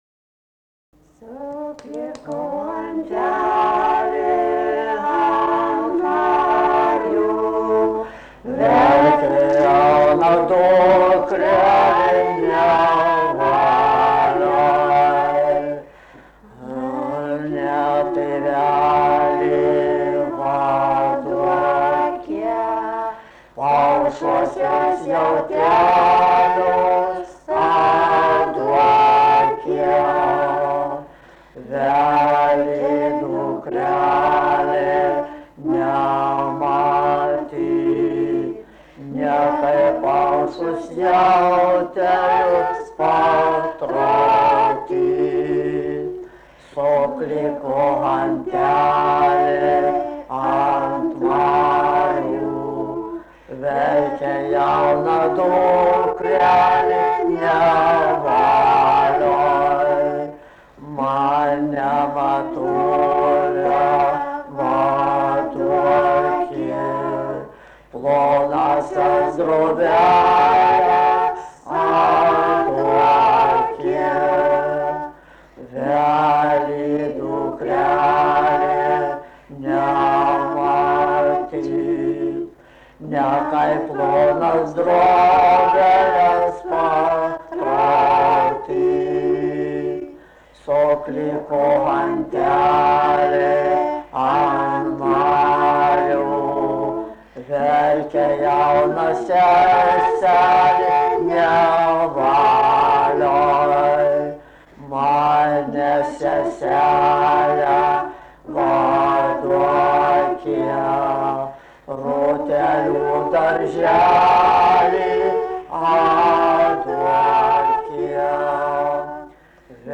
vokalinis
Dvi